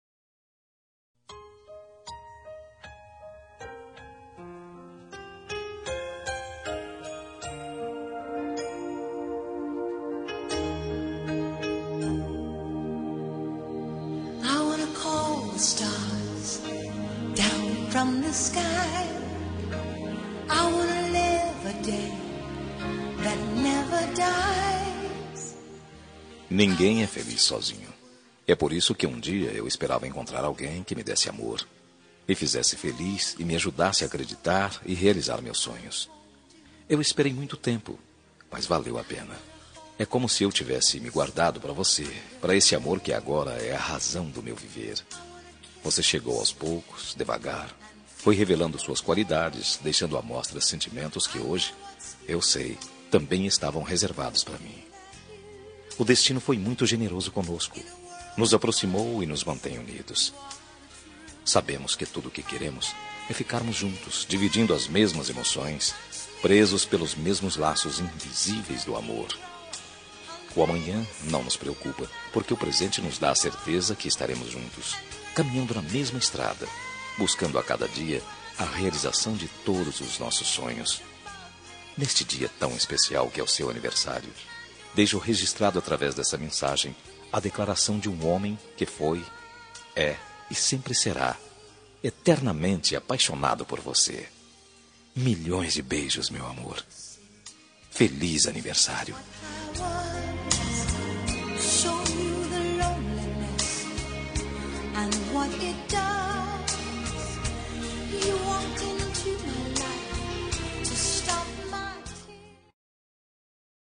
Telemensagem de Aniversário de Esposa – Voz Masculina – Cód: 1128